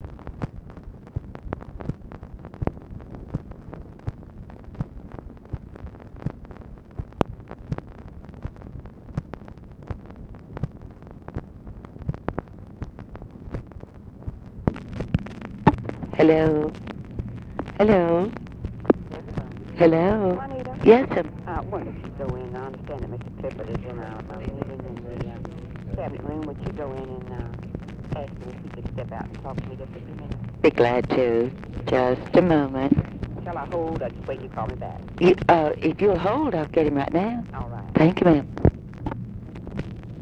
Conversation with LADY BIRD JOHNSON, August 29, 1966
Secret White House Tapes